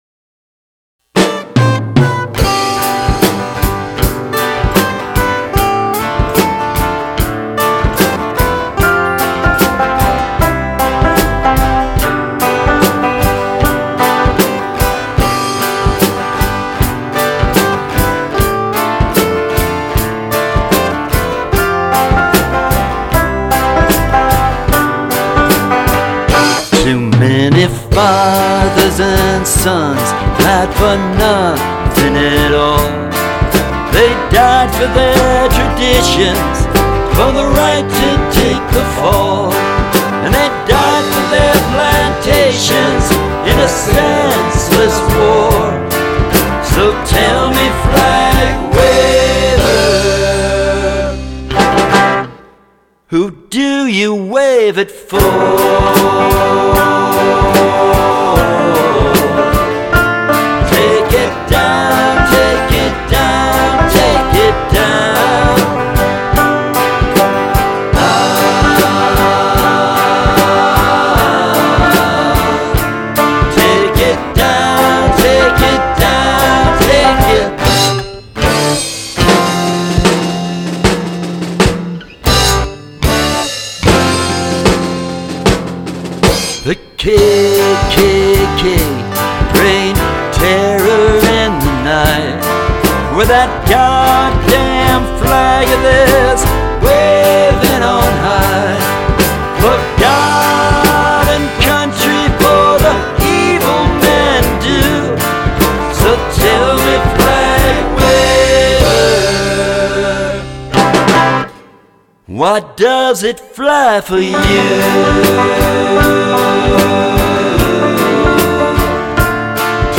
bass on tracks 6&9, vocals on tracks 2,6,&12...